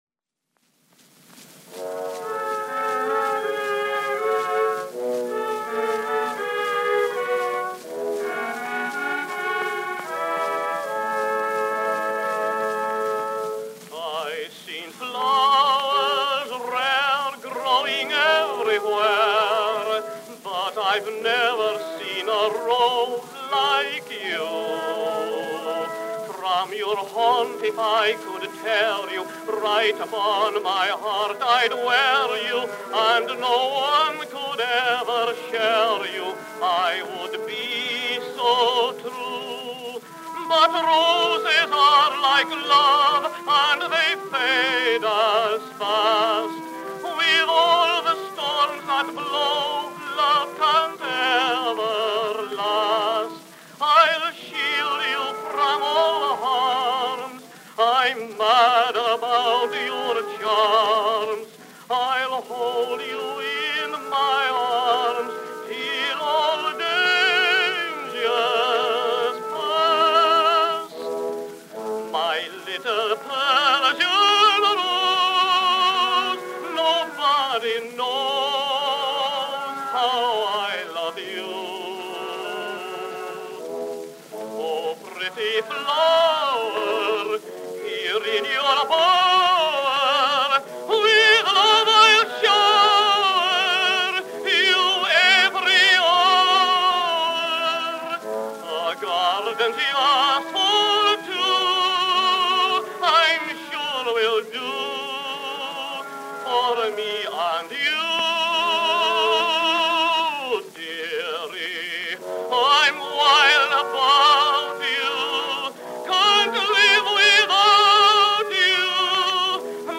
Tenor solo, orchestra accompaniment.
Popular music—1911-1920.